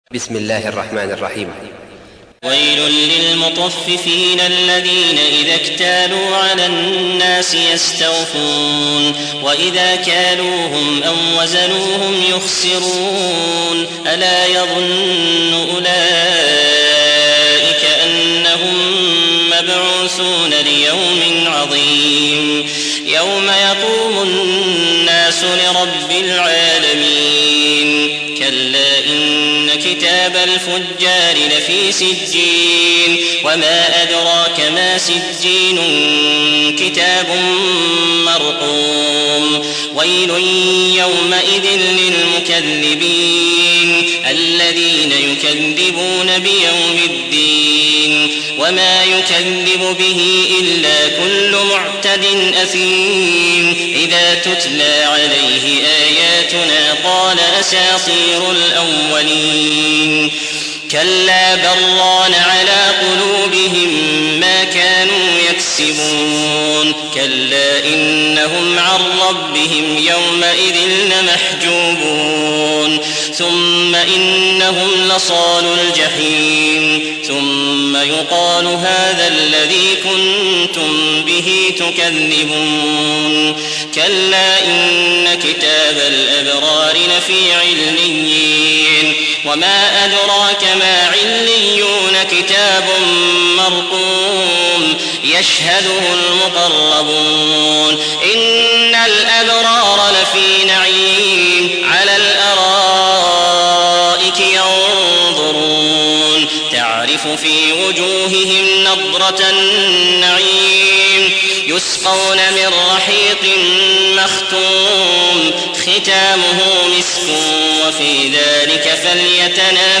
تحميل : 83. سورة المطففين / القارئ عبد العزيز الأحمد / القرآن الكريم / موقع يا حسين